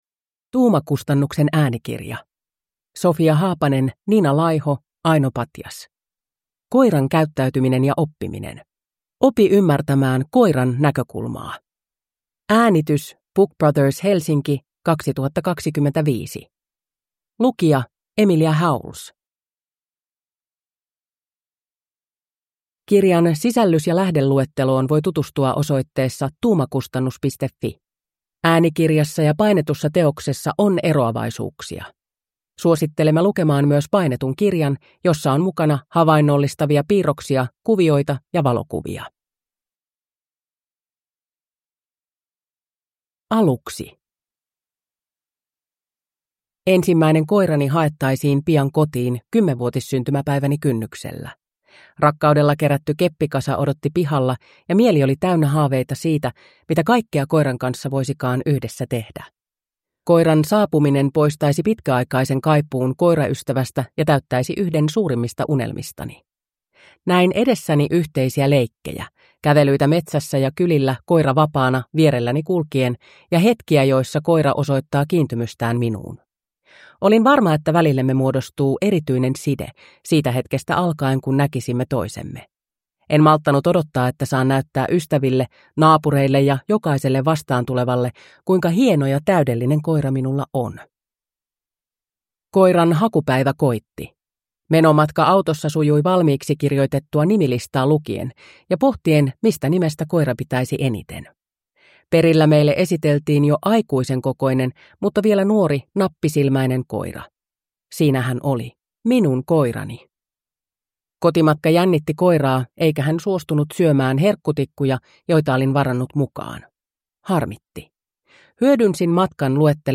Koiran käyttäytyminen ja oppiminen – Ljudbok